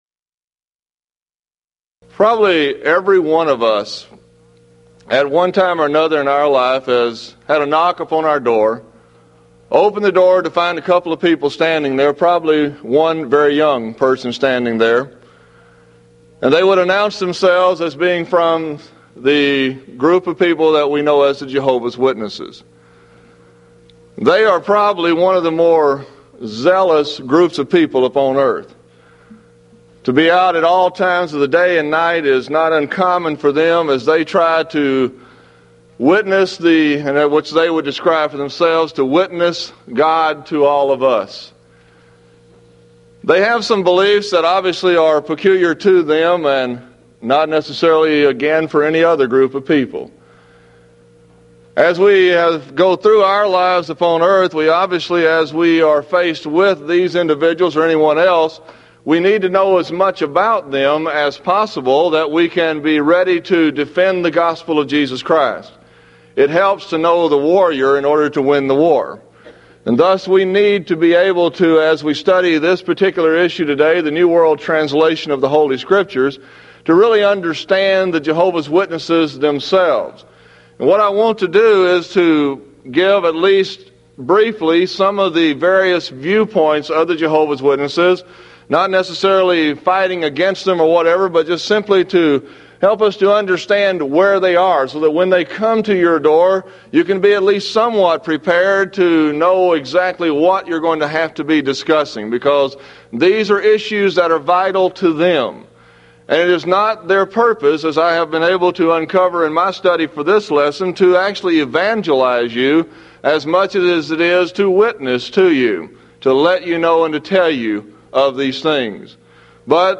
Event: 1995 Mid-West Lectures